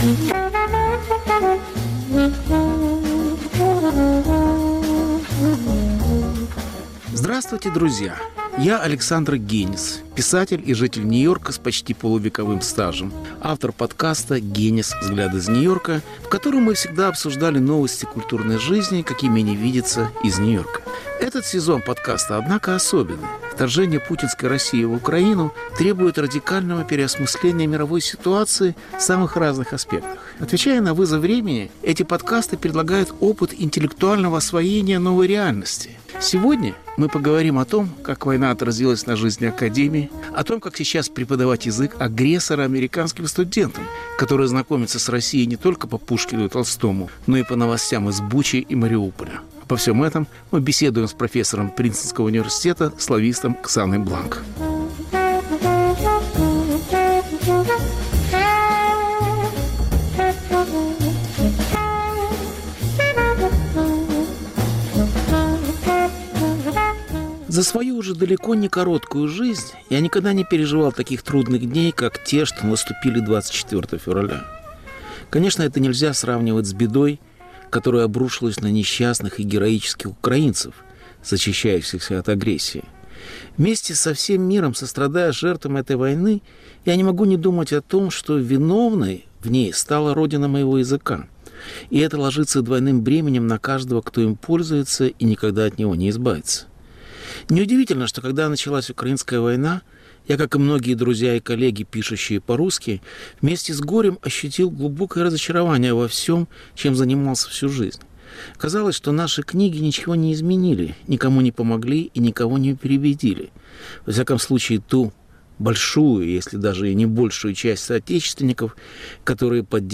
Разговор о языке агрессора